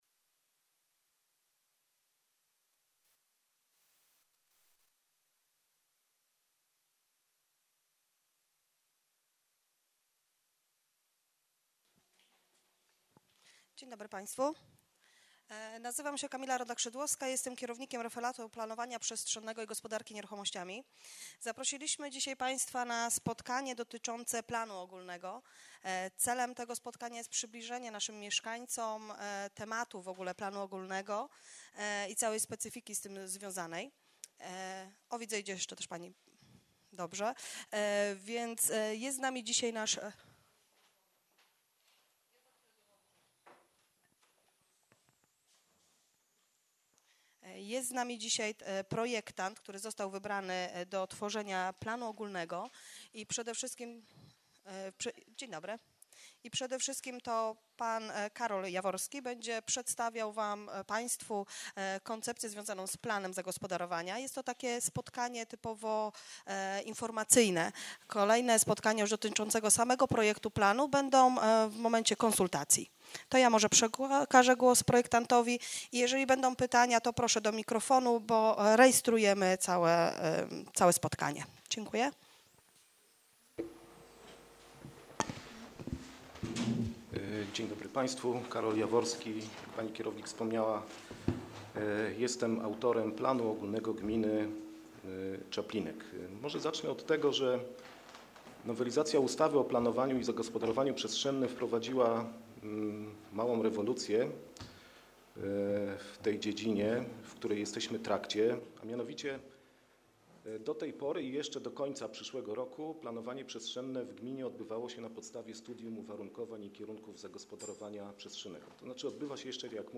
nagranie ze spotkania z dnia 10 października 2024 r.